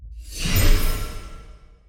x1_battle_mozhate_attack.wav